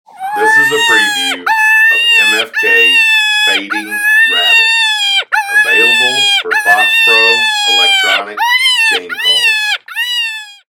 Recorded with the best professional grade audio equipment MFK strives to produce the highest